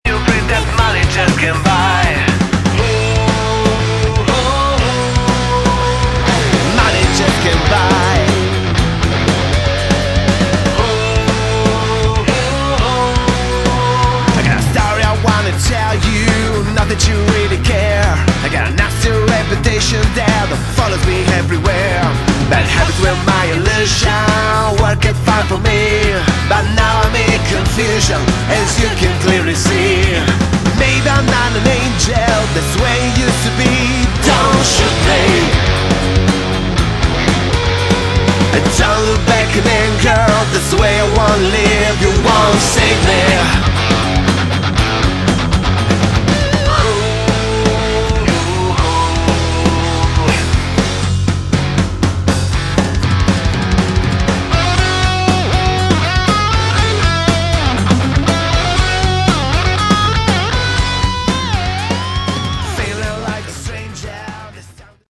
Category: Hard Rock
vocals
guitar, piano
bass
drums